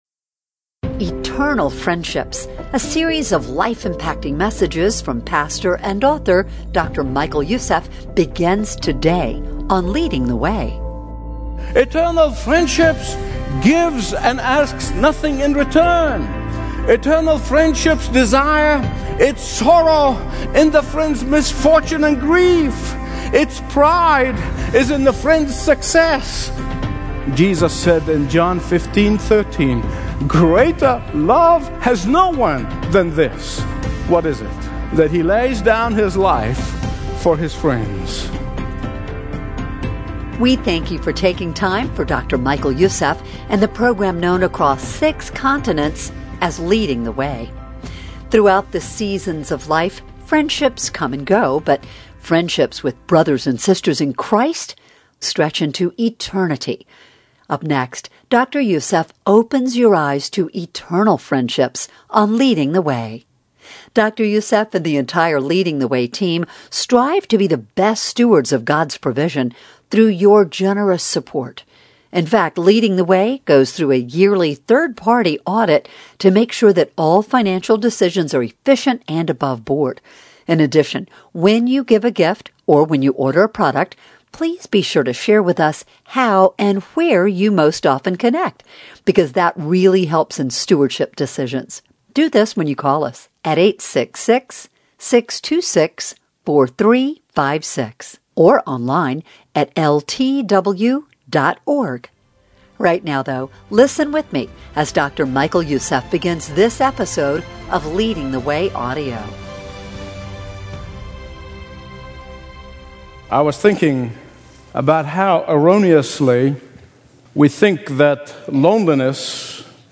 Stream Expository Bible Teaching & Understand the Bible Like Never Before